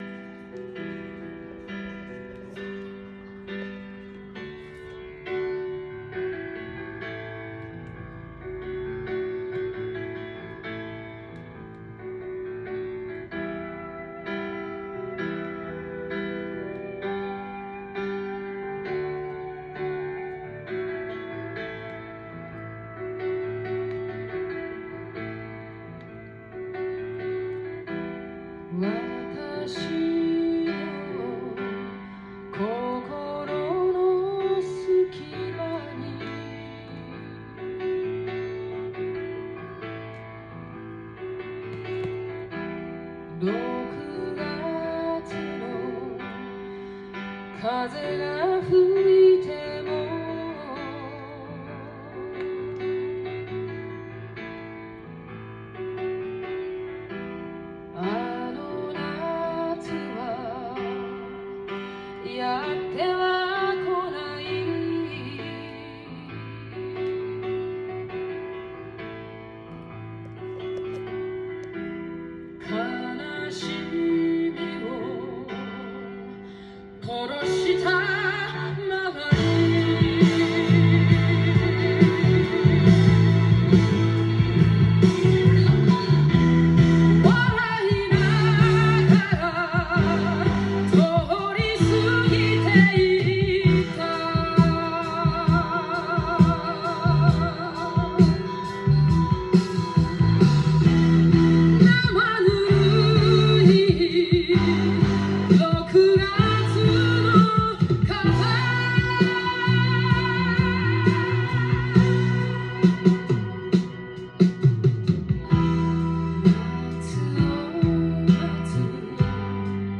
ジャンル：J-ROCK
店頭で録音した音源の為、多少の外部音や音質の悪さはございますが、サンプルとしてご視聴ください。